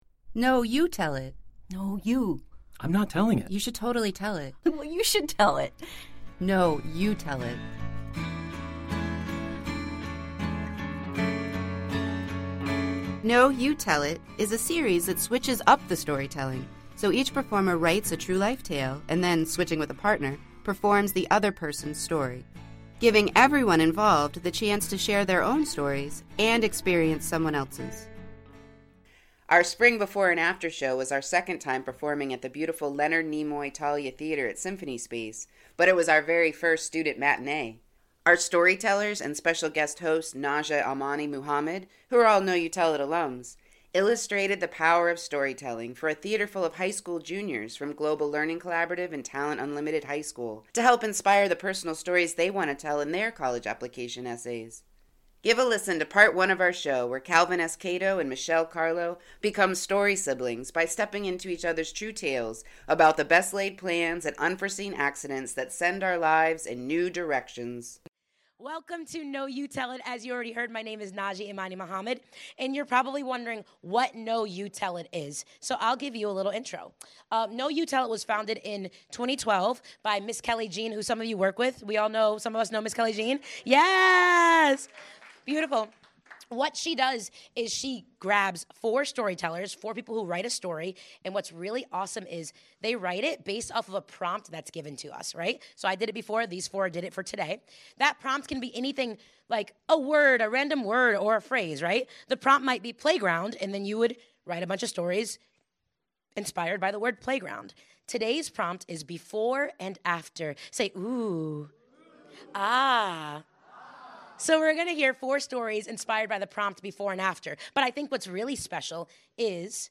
Our spring “Before & After” show was our second time performing at the beautiful Leonard Nimoy Thalia Theatre at Symphony Space on March 13, 2025, but it was our very first student matinee!